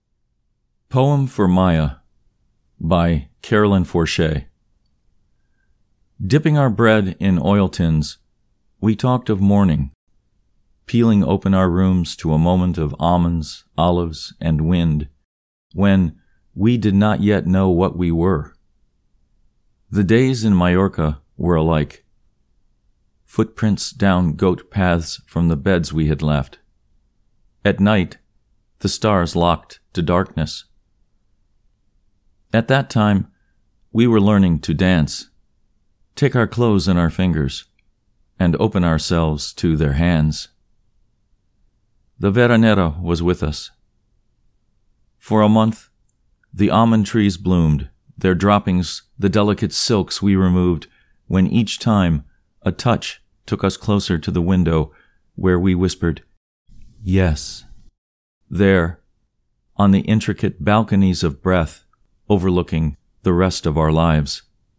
Poem for Maya © by Carolyn Forche (Recitation)